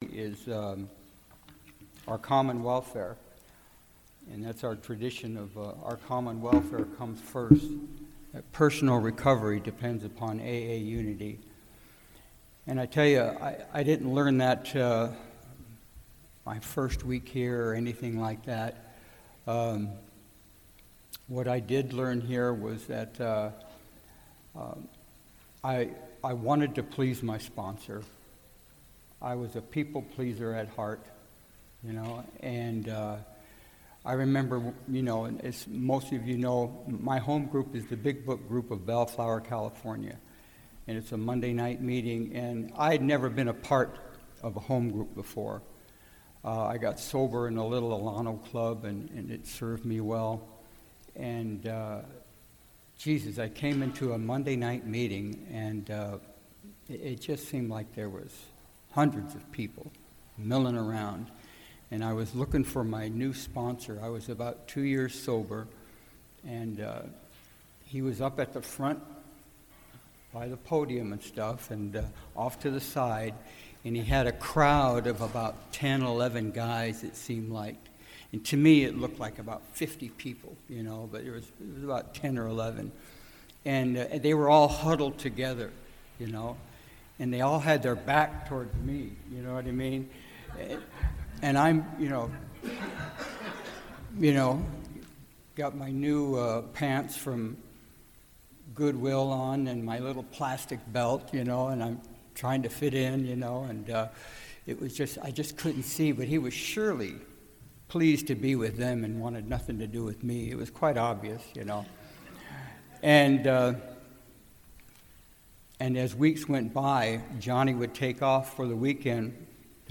Southern California AA Convention